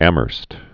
(ămərst), Jeffrey Baron Amherst. 1717-1797.